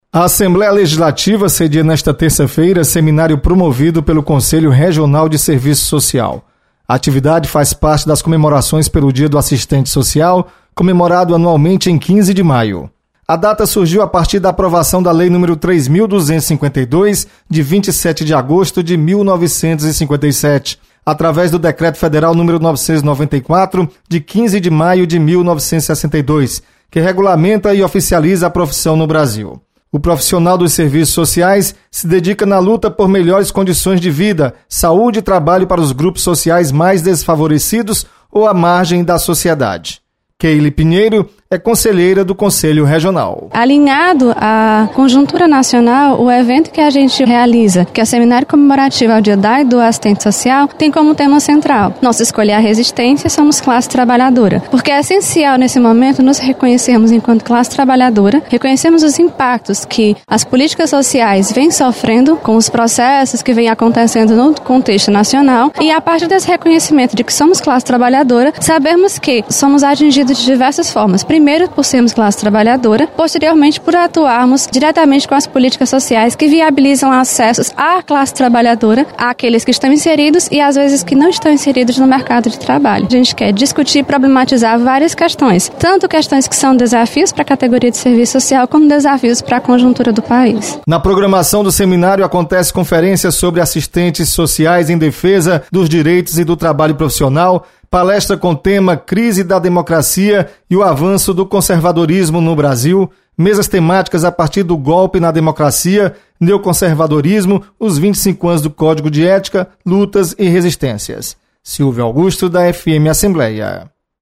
Assembleia Legislativa sedia seminário sobre serviço social. Repórter